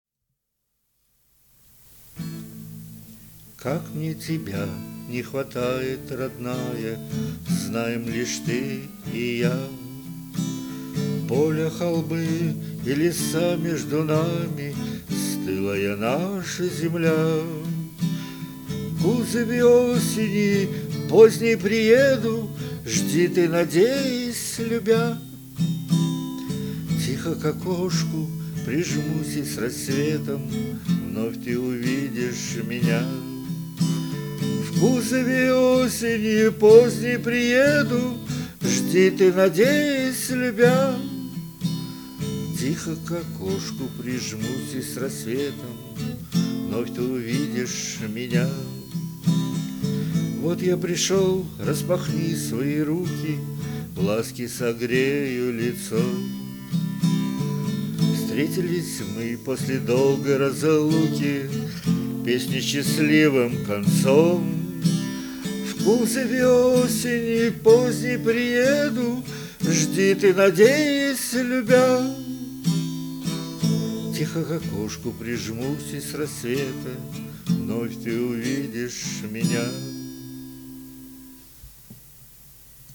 Музыкальный хостинг: /Авторская песня